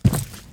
FootstepHeavy_Concrete 07.wav